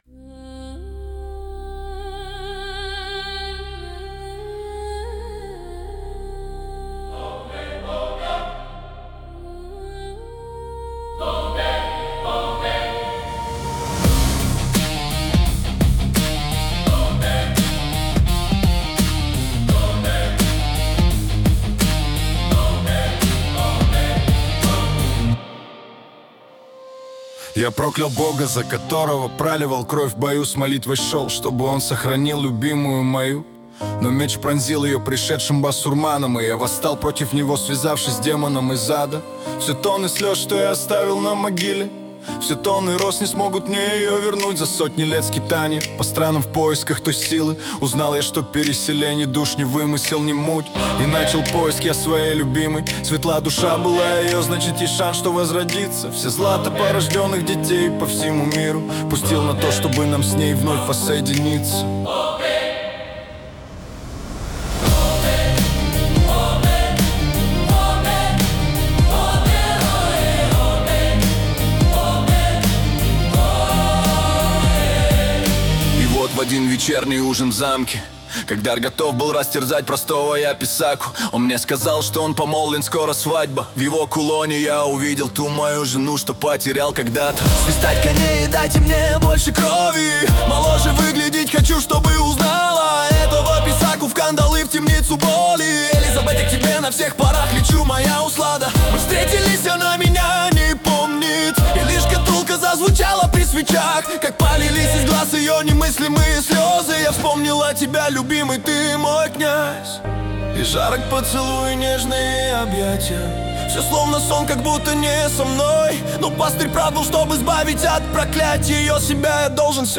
созданный с помощью искусственного интеллекта.